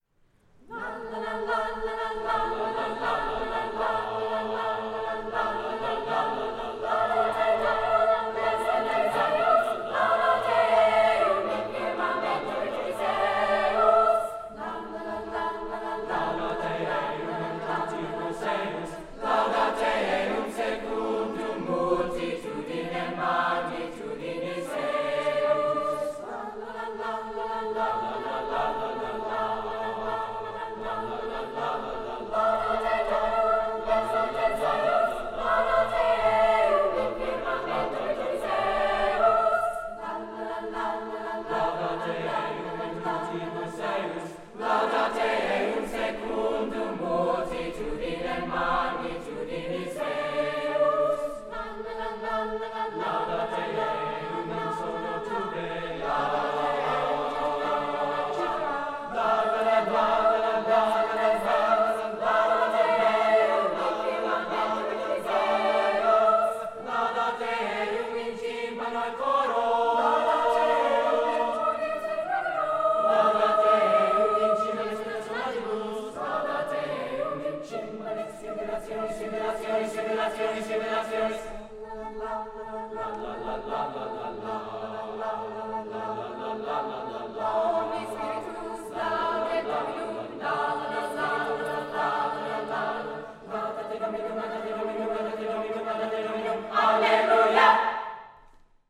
Brookline High School Spring Music Festival
Concert Choir